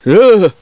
Download Half-life Scientist Fear sound effect for free.
Half-life Scientist Fear